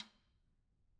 Percussion
Snare2-taps_v1_rr1_Sum.wav